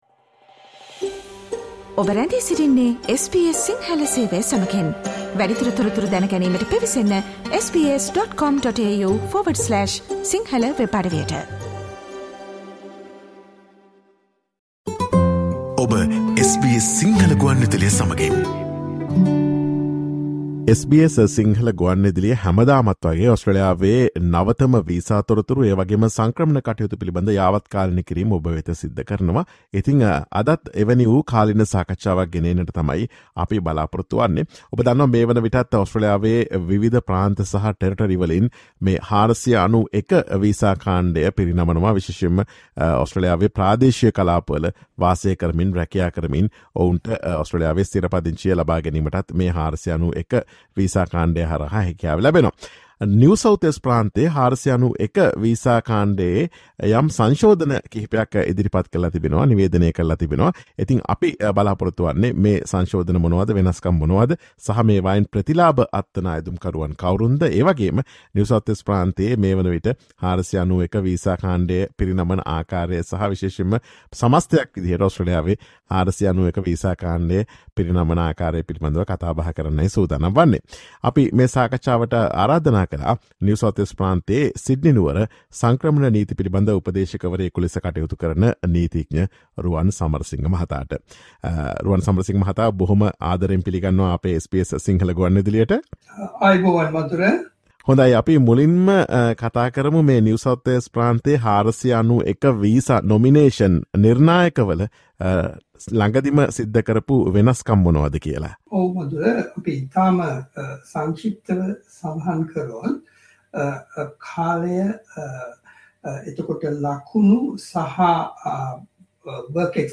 Listen to SBS Sinhala Radio's discussion on the latest amendments to NSW 491 visa nomination criteria.